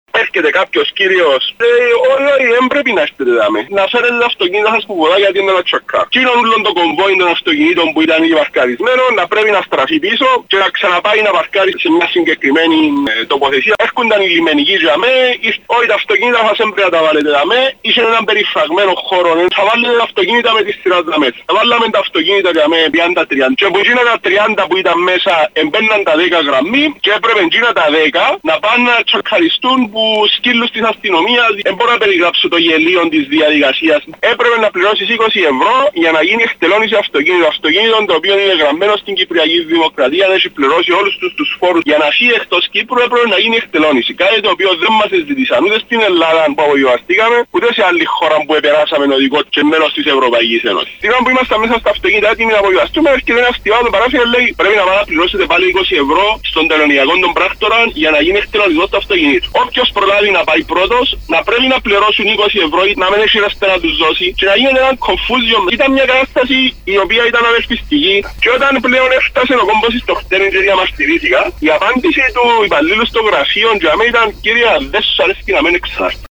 Καταγγελία στην πρωϊνή ενημερωτική εκπομπή στο Κανάλι 6: